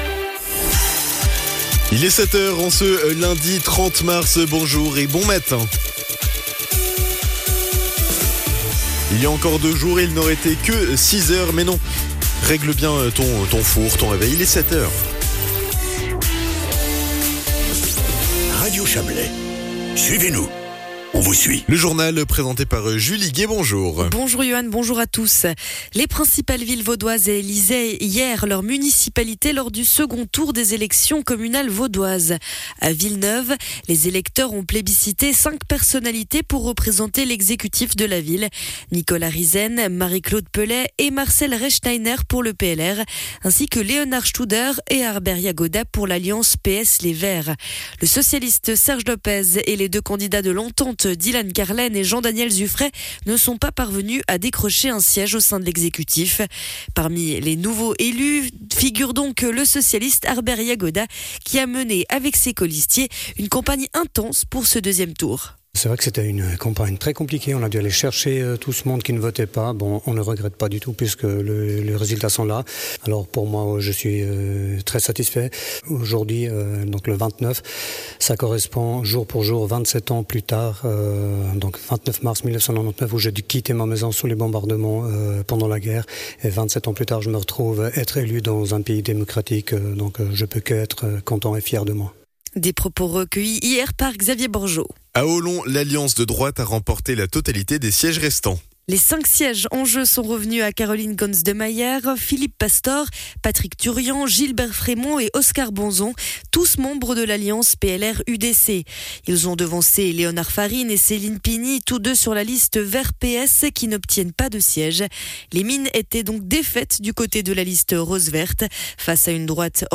Le journal de 7h00 du 30.03.2026